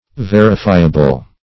Verifiable \Ver"i*fi`a*ble\, a.